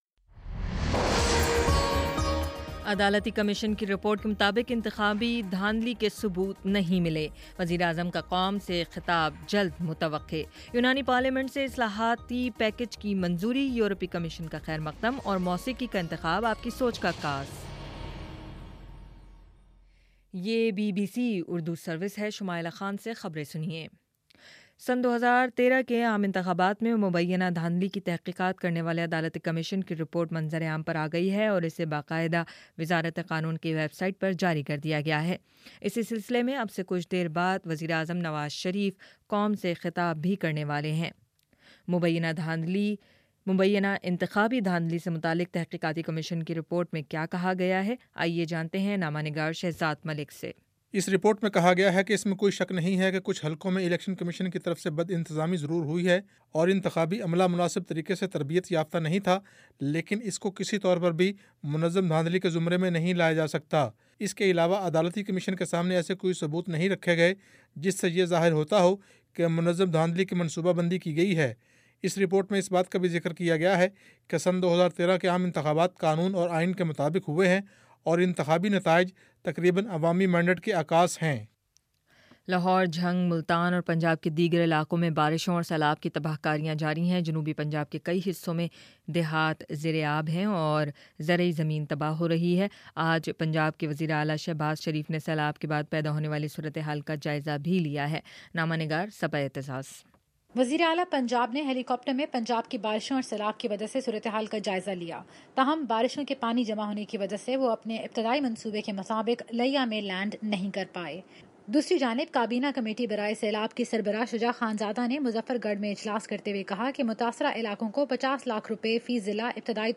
جولائی 23: شام سات بجے کا نیوز بُلیٹن